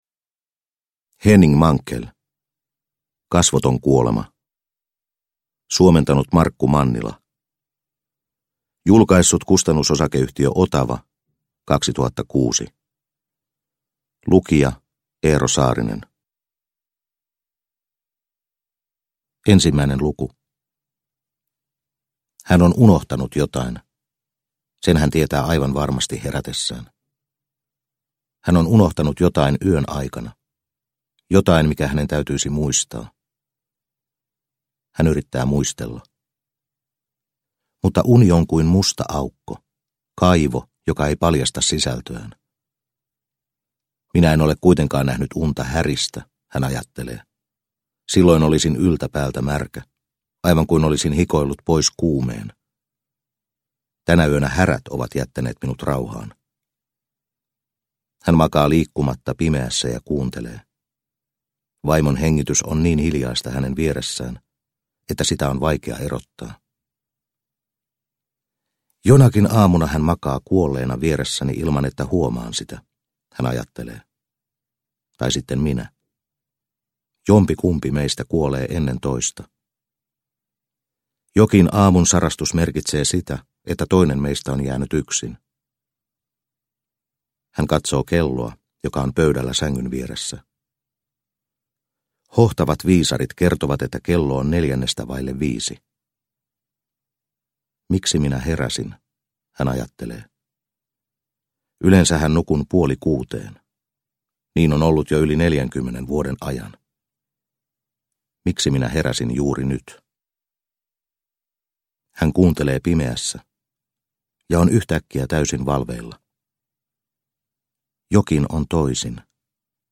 Kasvoton kuolema – Ljudbok – Laddas ner